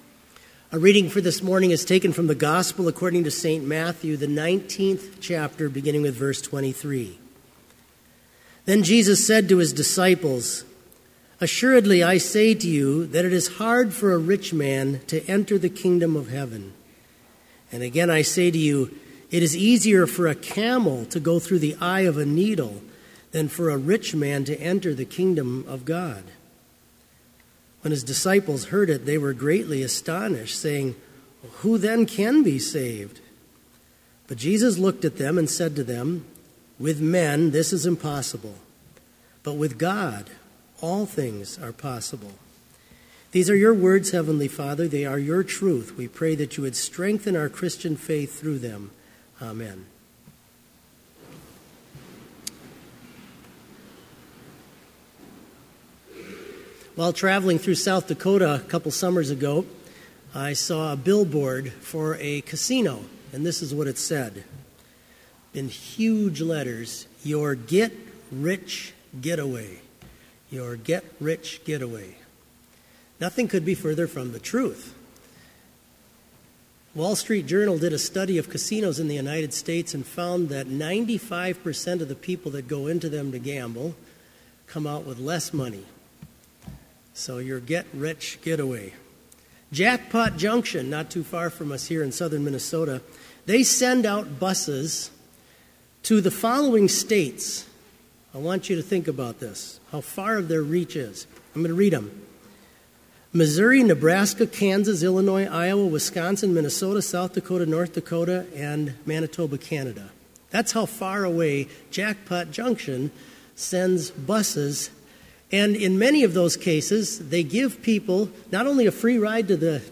Chapel worship service held on March 12, 2018, BLC Trinity Chapel, Mankato, Minnesota,
Complete service audio for Chapel - March 12, 2018